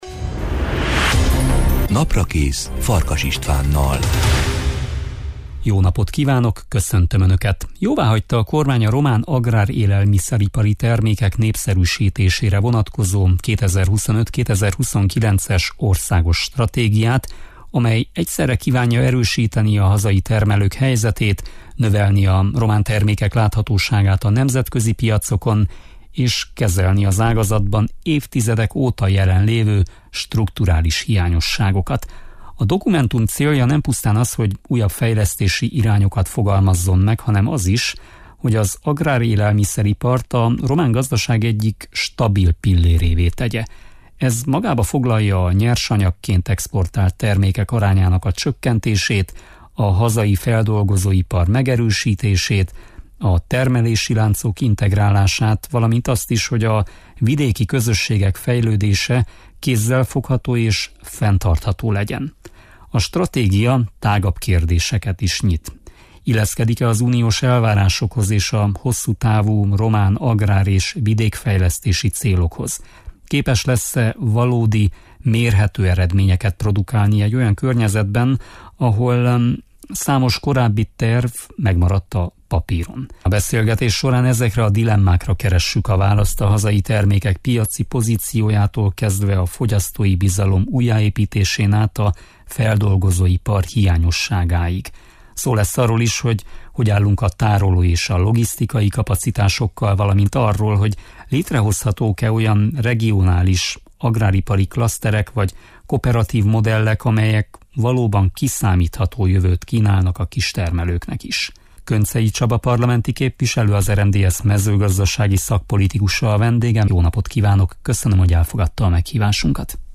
Könczei Csaba parlamenti képviselő, az RMDSZ mezőgazdasági szakpolitikusa a vendégem.